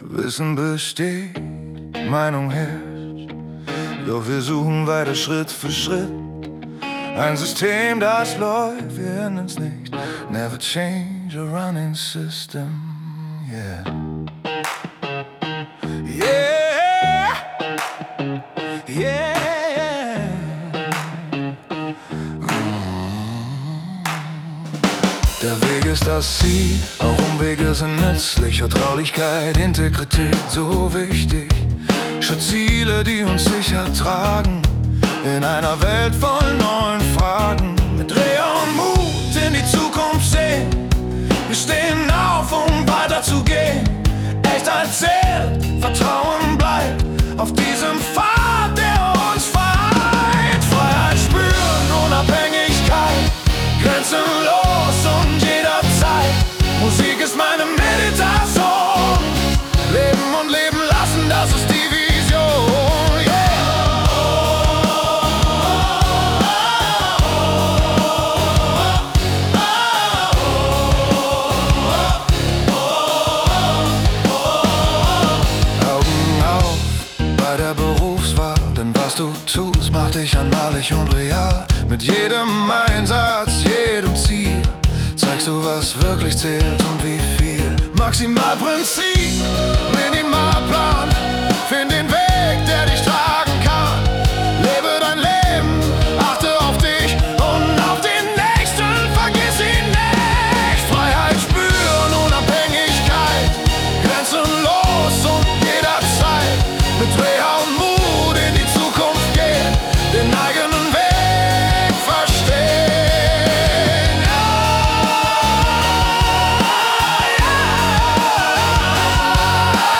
Version mit männlicher Stimme: